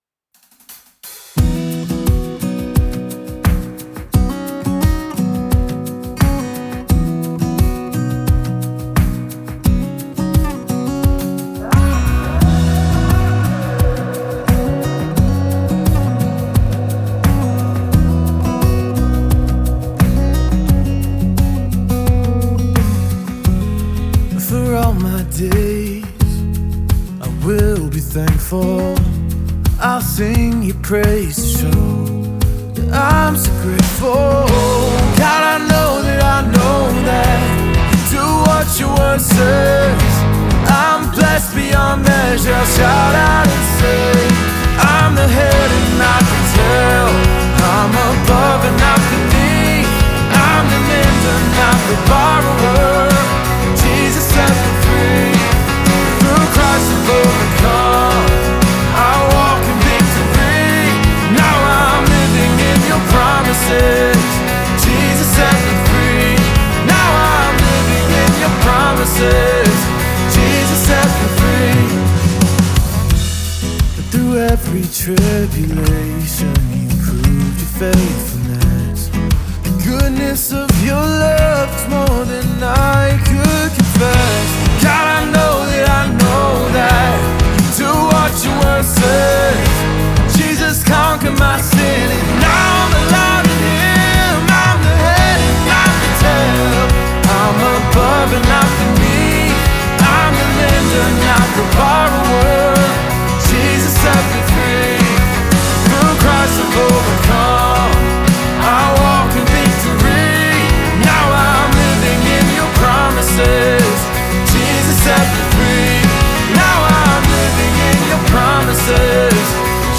Discover a powerful anthem of freedom and faith